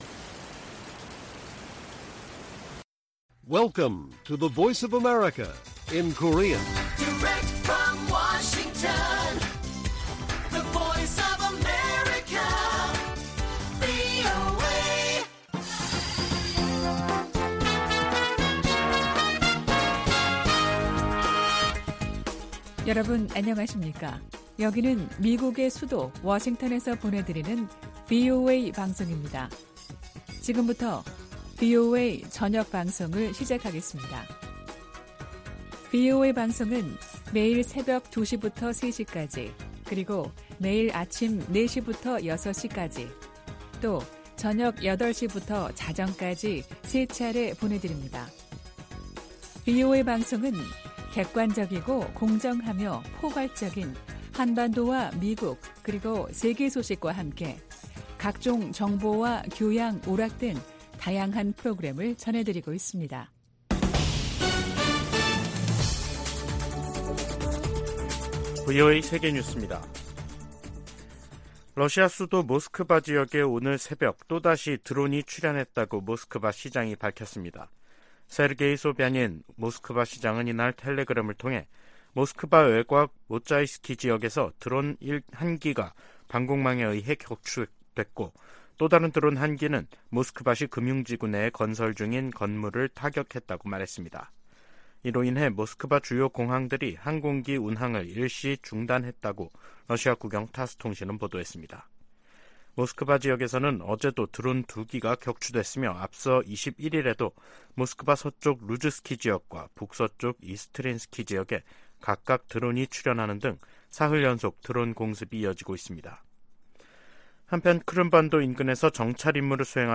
VOA 한국어 간판 뉴스 프로그램 '뉴스 투데이', 2023년 8월 23일 1부 방송입니다. 미 국무부는 탄도미사일 기술이 이용되는 북한의 모든 발사는 유엔 안보리 결의 위반임을 거듭 지적했습니다. 미 국방부가 북한의 위성 발사 통보와 관련해 한국·일본과 긴밀히 협력해 대응하겠다고 밝혔습니다. 미국 정부가 미국인의 북한 여행을 금지하는 조치를 또다시 연장했습니다.